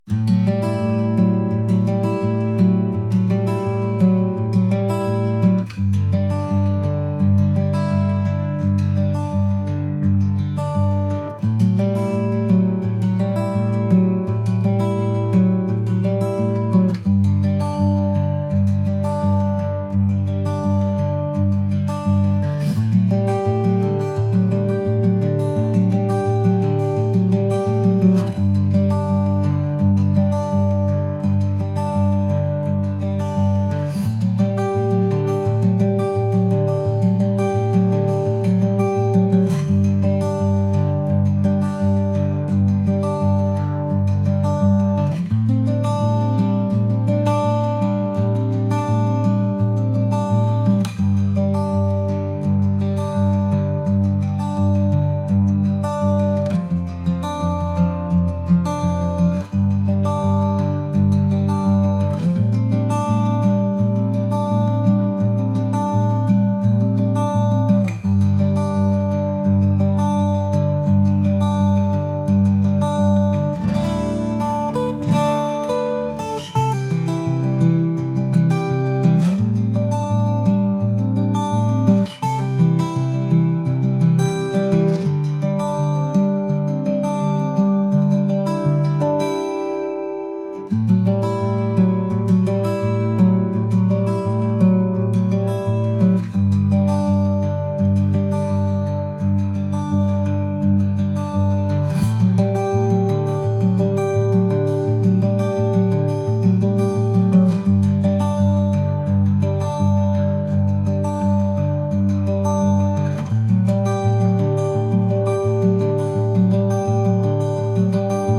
indie | acoustic | folk